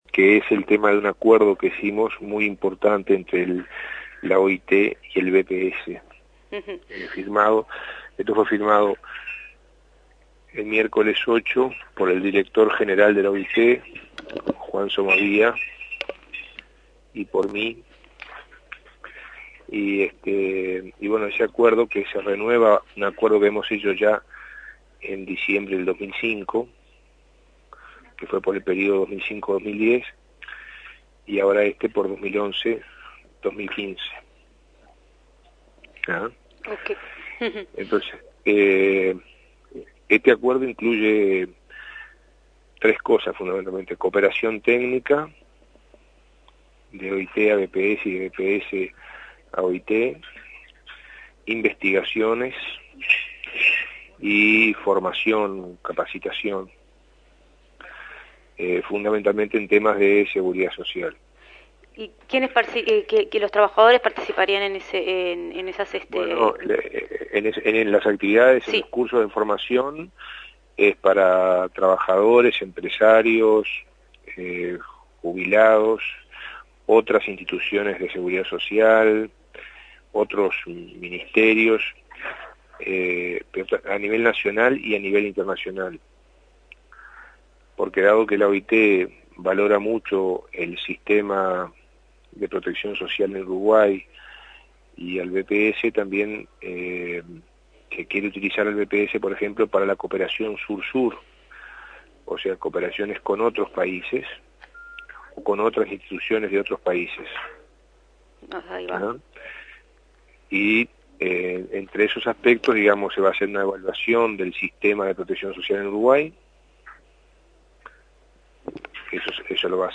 En entrevista con la Secretaria de Comunicaci�n, Murro explic� que el pasado 8 de junio nuestro pa�s renov� un convenio que en el a�o 2010 hab�a cesado.